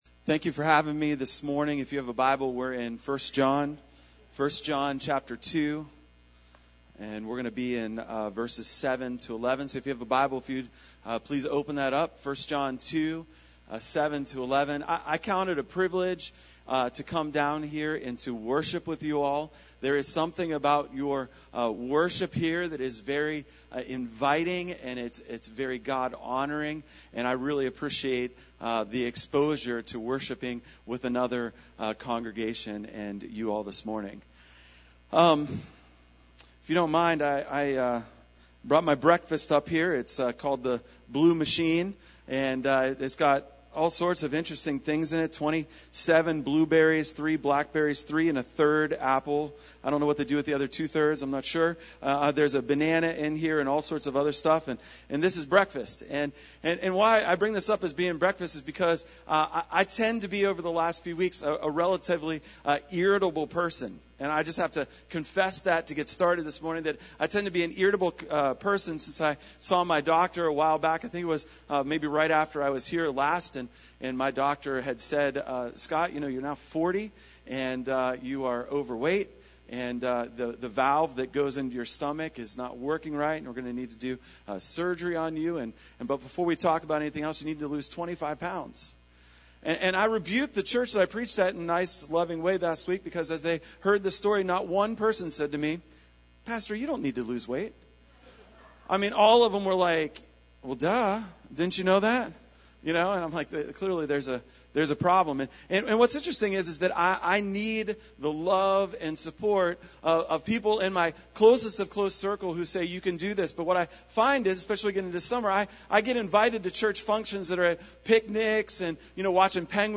There was a very quick power outage around 12 minutes into this sermon.